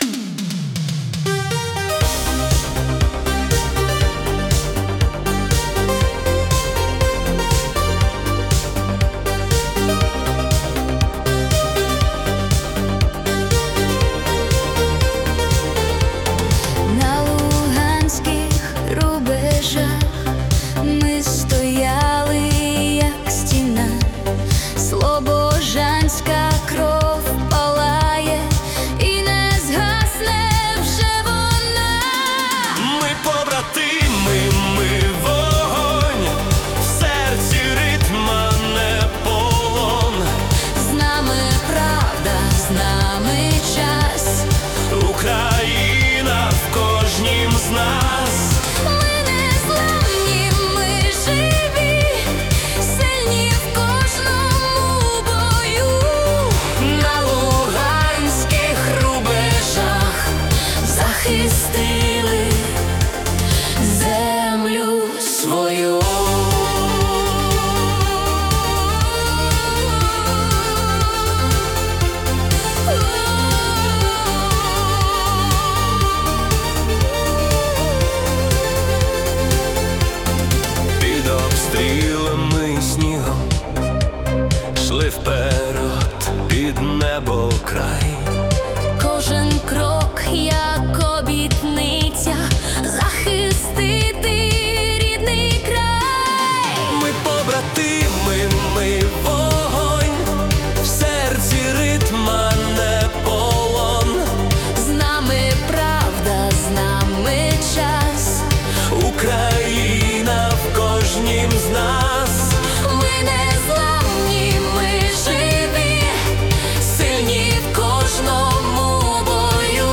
🎵 Жанр: Epic Synth-Pop / Italo Disco